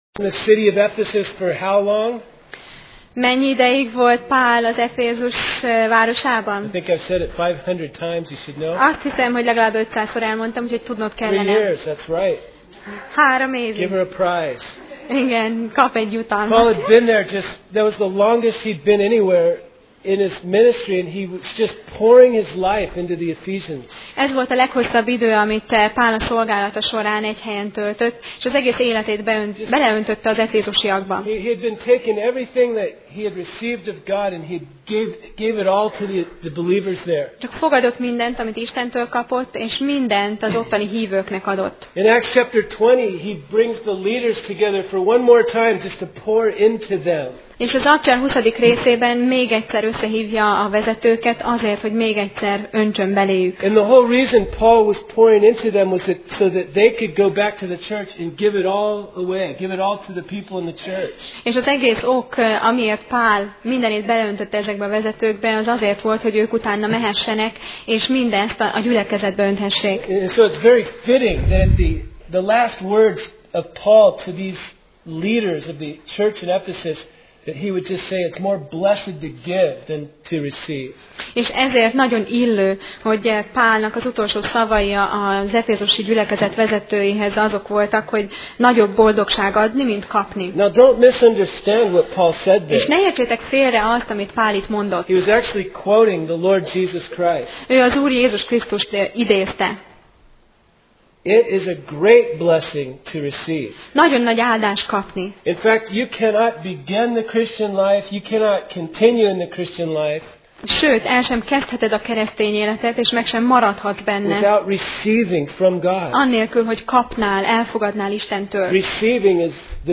Sorozat: Apostolok cselekedetei Passage: Apcsel (Acts) 21:1-14 Alkalom: Vasárnap Reggel